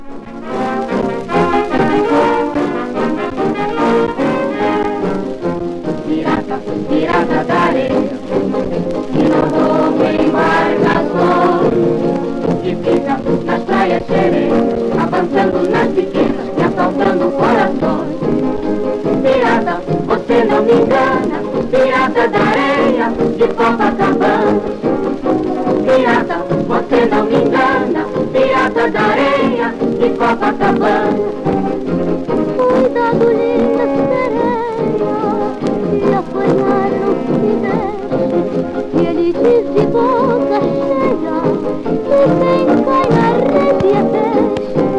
ジャケスレ汚れシール貼付有　盤良好　元音源に起因するノイズ有